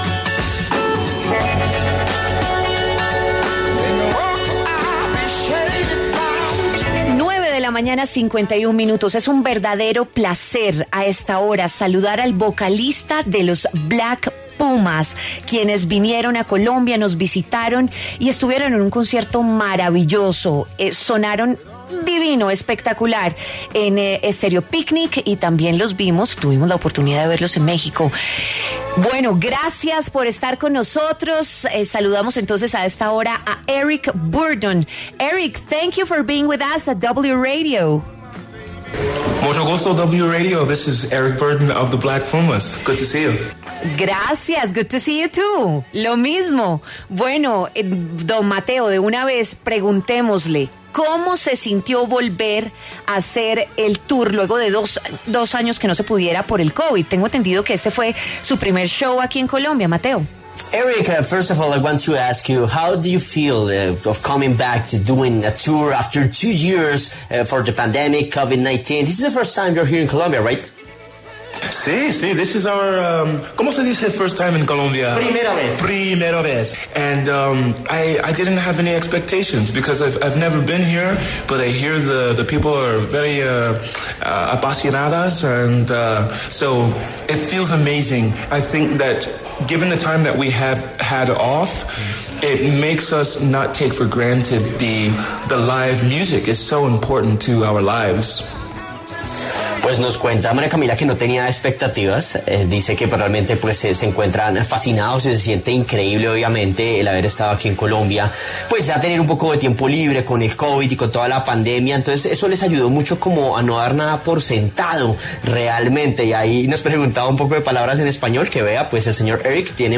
Eric Burton, vocalista de Black Pumas, habló en W Fin de Semana a propósito de su presentación en Bogotá en el Festival Estéreo Picnic.
En el encabezado escuche la entrevista completa con Eric Burton, vocalista de Black Pumas.